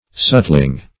Search Result for " sutling" : The Collaborative International Dictionary of English v.0.48: Sutling \Sut"ling\, a. Belonging to sutlers; engaged in the occupation of a sutler.